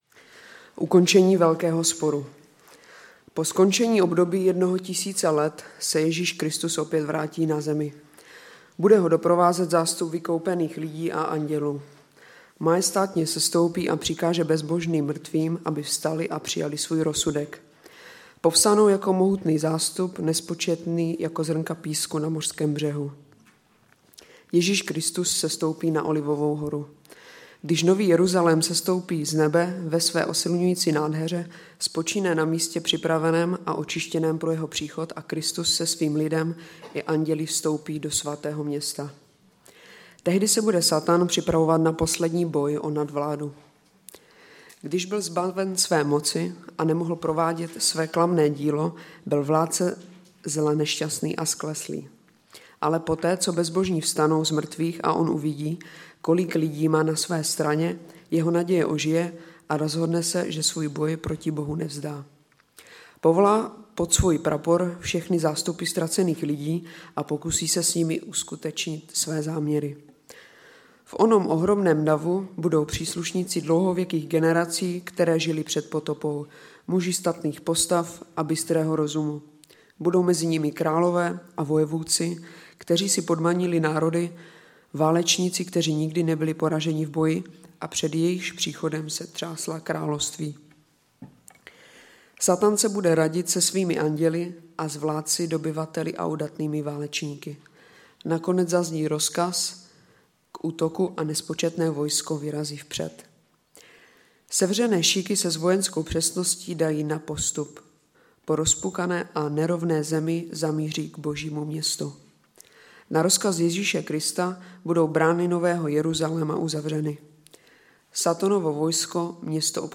Sbor Ostrava-Radvanice. Shrnutí přednášky začíná kolem 13-té minuty.